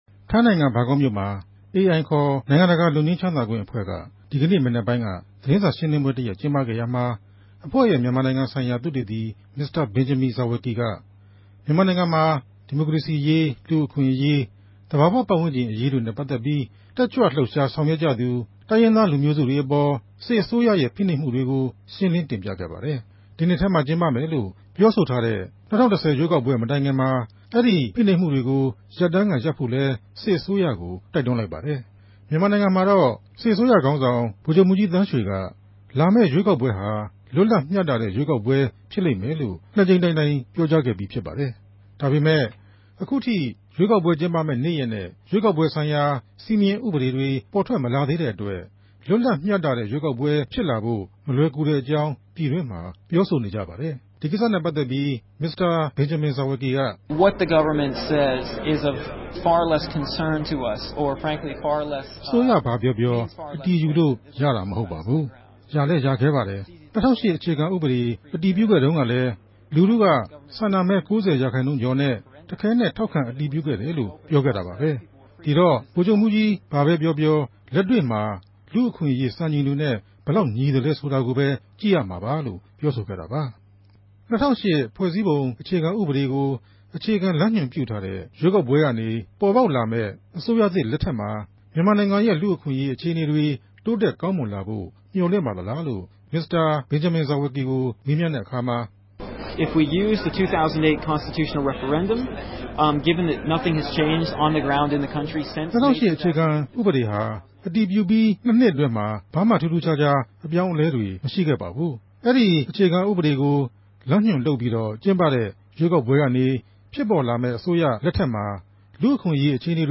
တြေႚဆုံမေးူမန်းခဵက်။